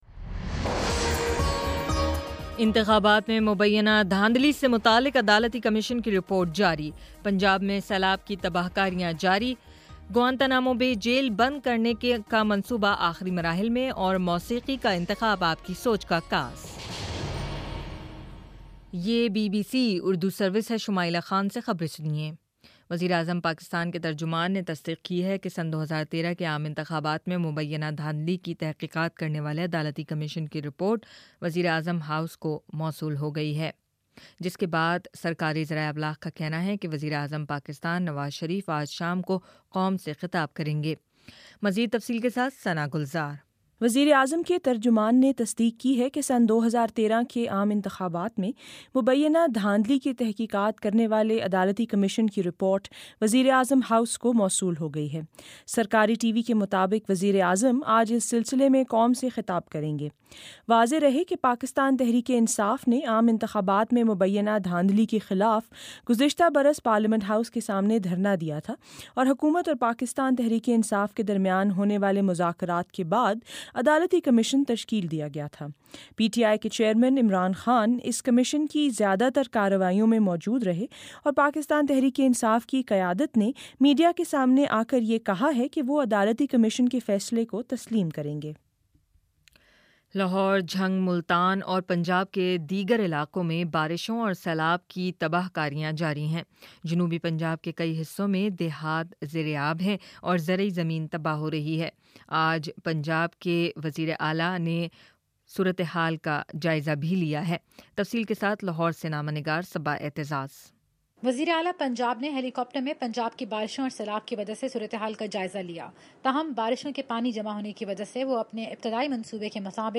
جولائی 23: شام چھ بجے کا نیوز بُلیٹن